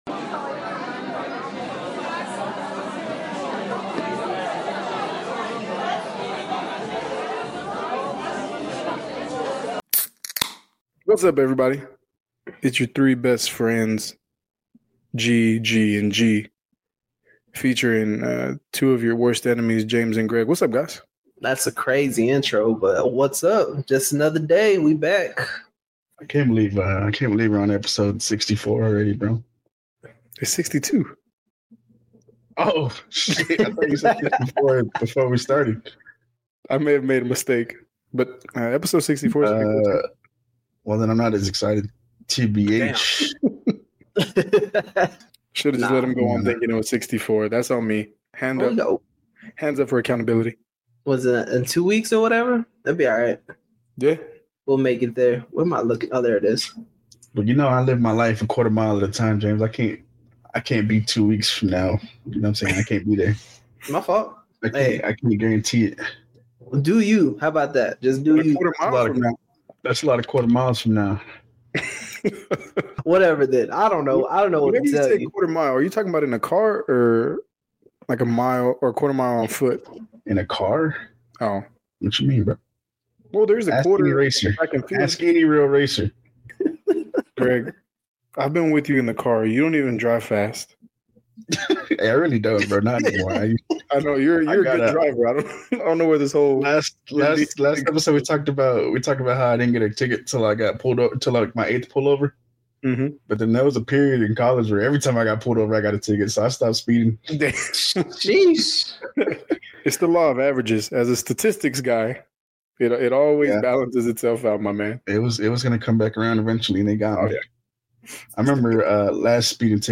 Welcome to Brews & Banter the podcast where we have lively conversations about everything under the sun. Join us as we explore a wide range of topics, from funny anecdotes to thought-provoking discussions.